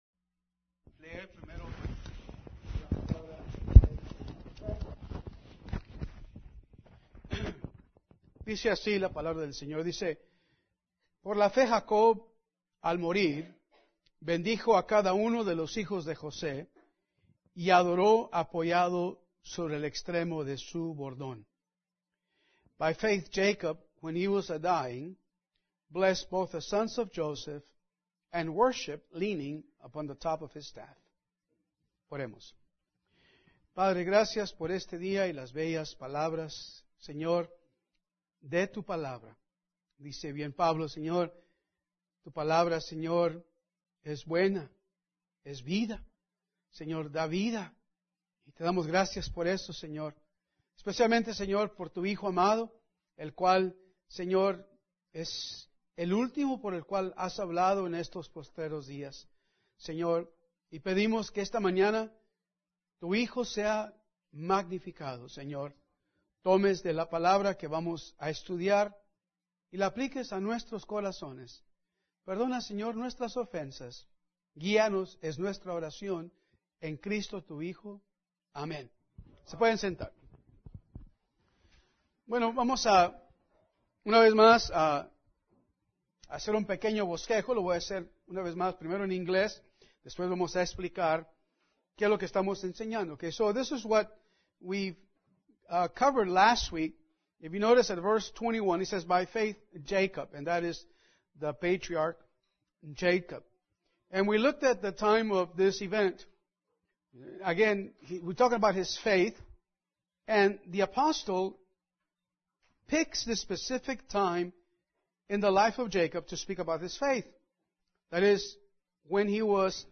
Domingo por la mañana – Hebreos 11